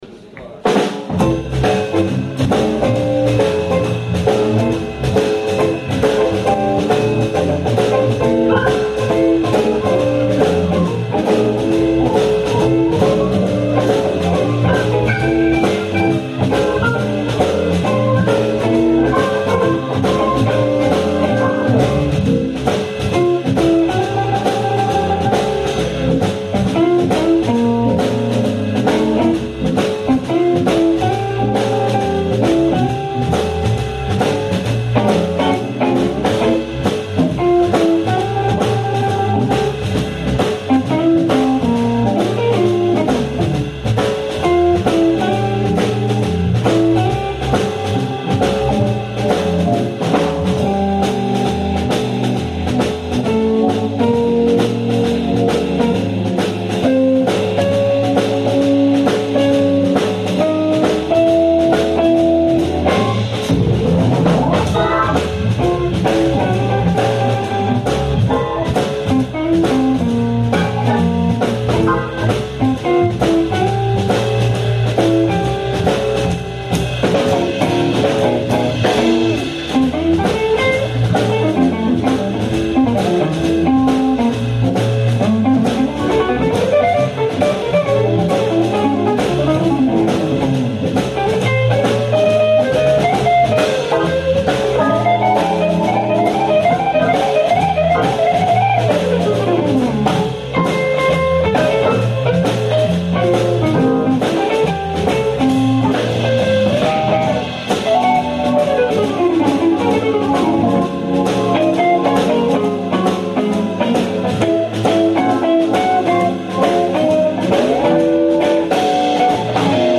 Un gentil spectateur a enregistré la totalité du concert de vendredi dernier sur un minidisc....la qualité n'est pas au rendez vous mais c'est ecoutable
guitare
batterie
La batterie couvre pas mal..mais bon, c'est du live!